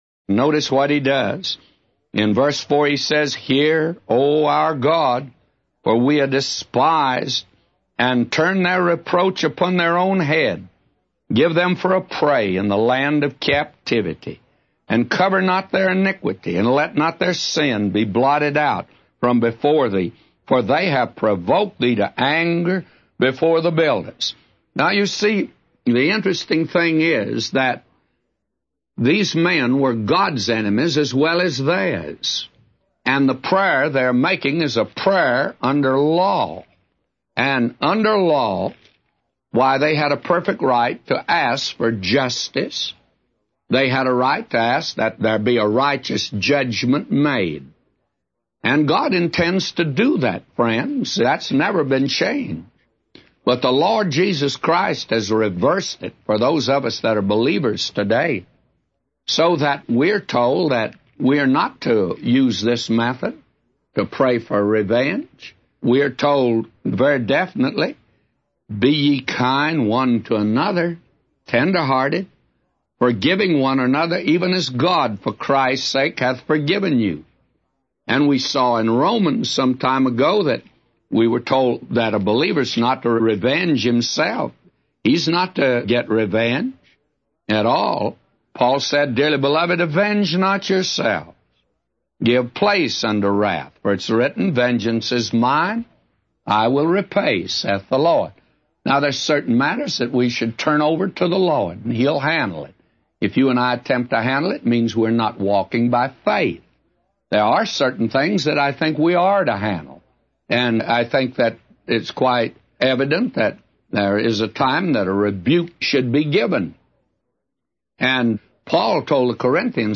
A Commentary By J Vernon MCgee For Nehemiah 4:4-999